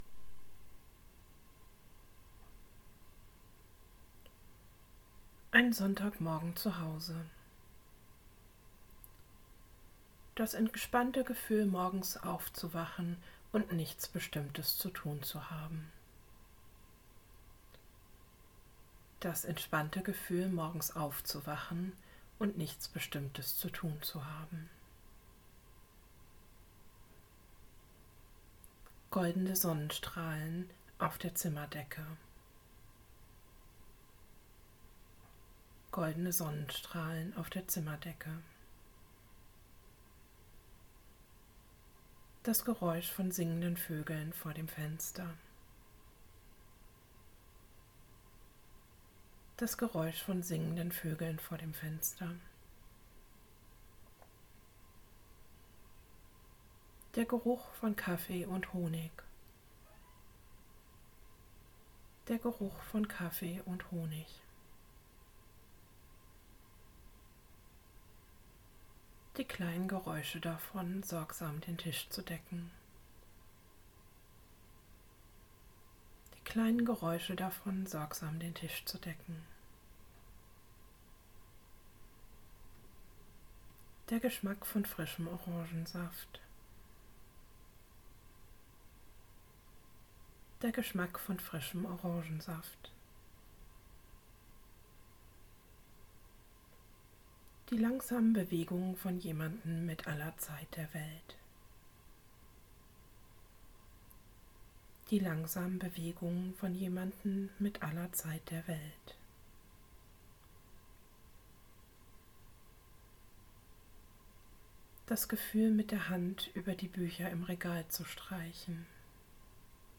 In dieser Kurzversion bekommt ihr Anleitung, euch eine Sinneserfahrung nach der anderen vorzustellen. Die Anleitung wird zweimal wiederholt und dann werdet ihr direkt zum nächsten Bild oder Sinn übergeleitet.